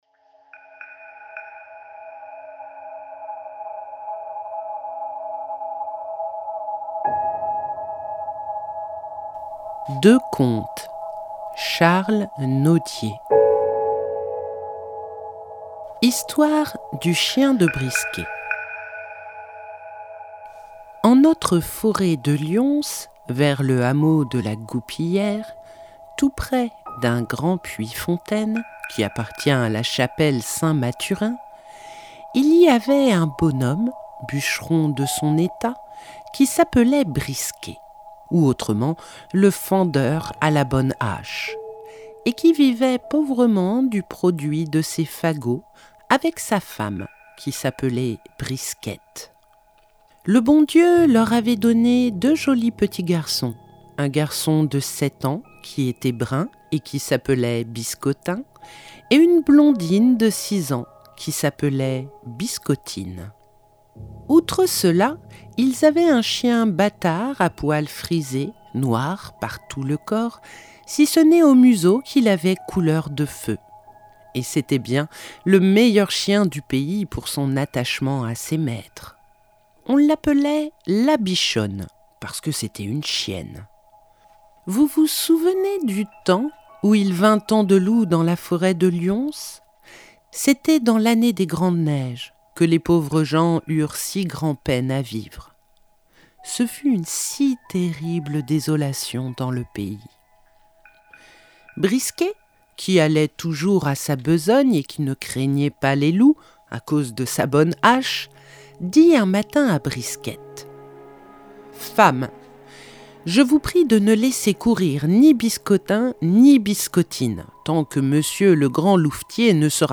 Deux contes (31:12)